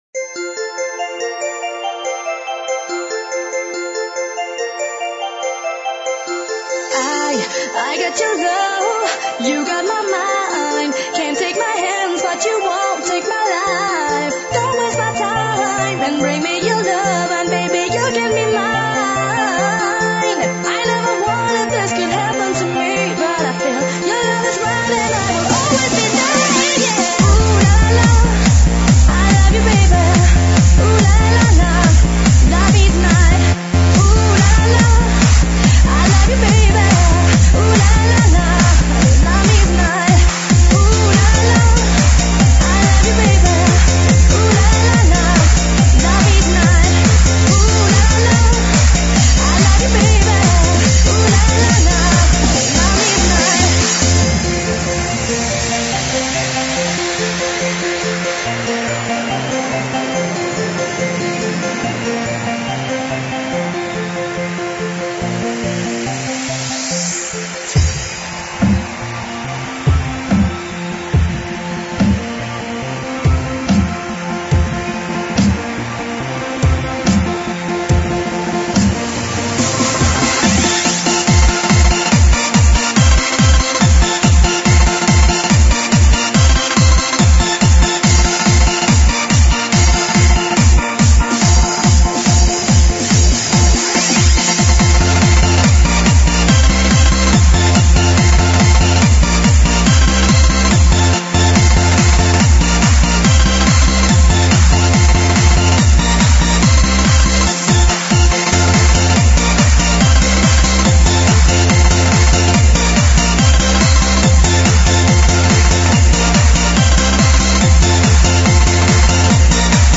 Жанр:Super/Club/Dance